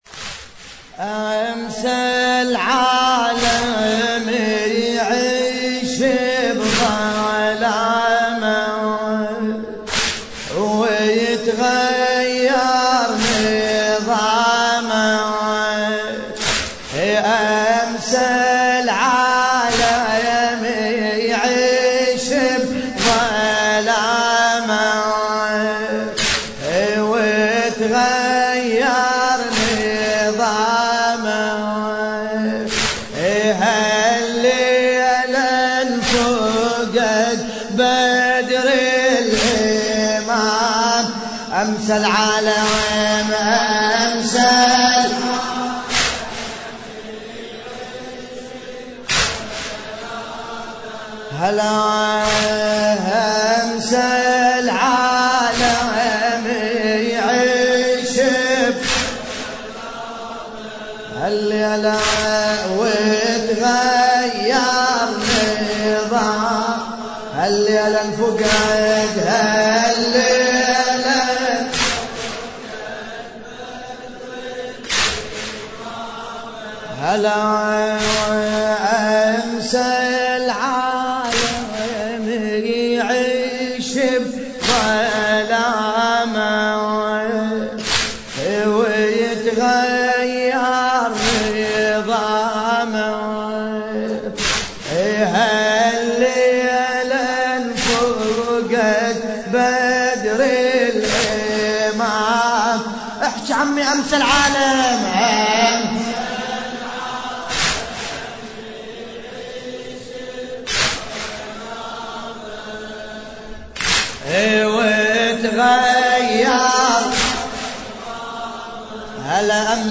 مراثي الامام العسكري (ع)